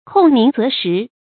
控名責實 注音： ㄎㄨㄙˋ ㄇㄧㄥˊ ㄗㄜˊ ㄕㄧˊ 讀音讀法： 意思解釋： 控：引；責：求。